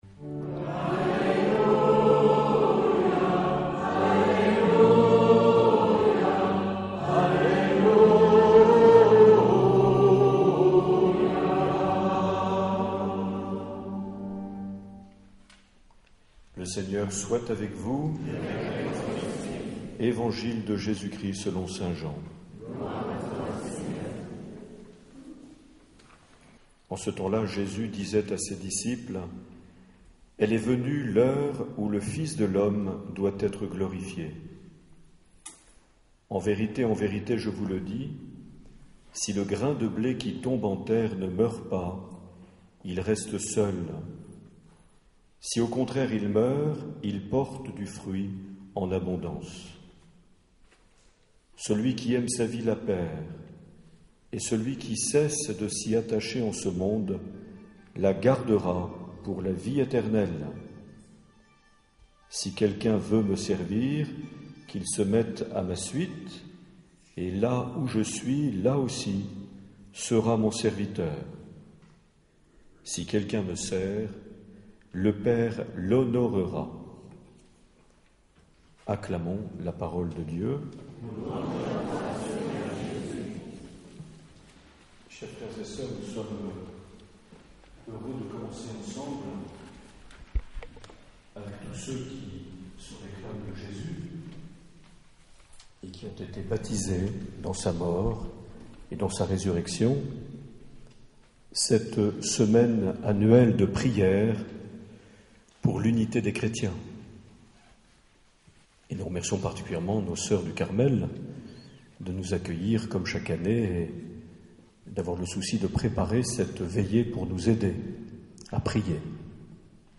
18 janvier 2012 - Carmel de Bayonne - Célébration oecuménique
Les Homélies
Une émission présentée par Monseigneur Marc Aillet